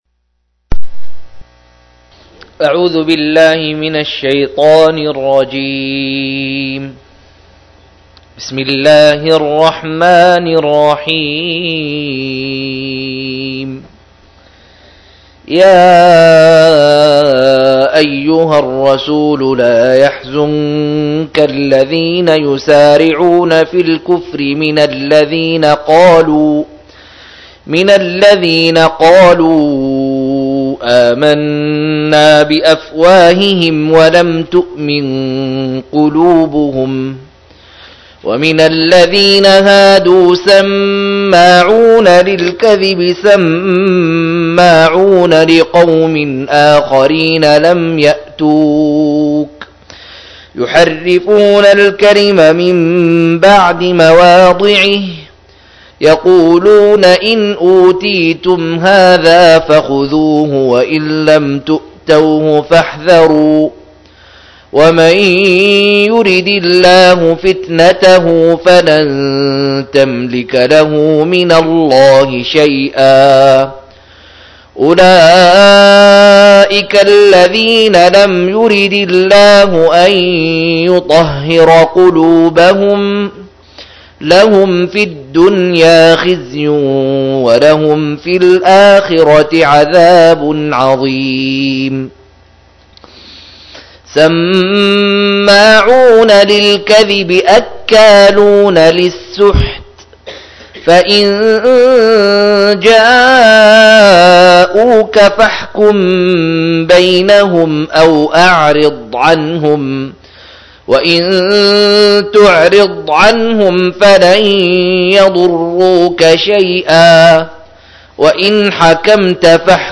116- عمدة التفسير عن الحافظ ابن كثير رحمه الله للعلامة أحمد شاكر رحمه الله – قراءة وتعليق –